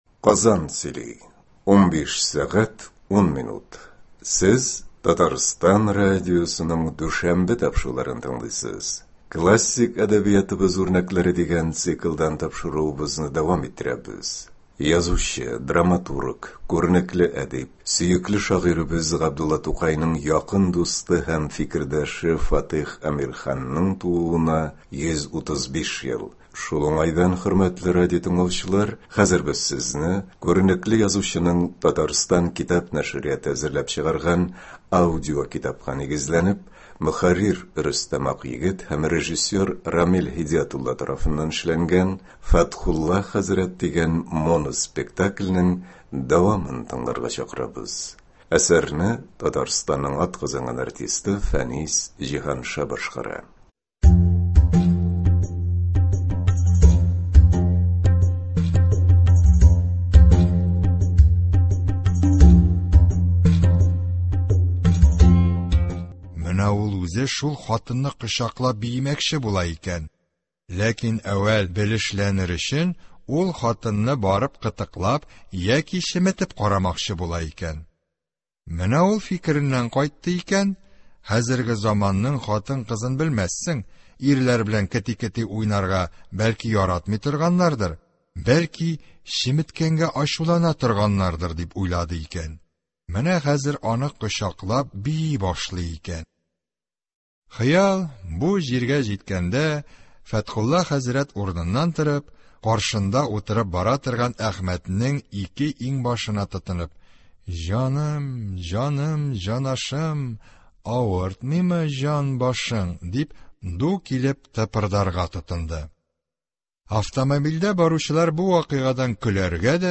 Фатыйх Әмирхан. “Фәтхулла хәзрәт”. Моноспектакль.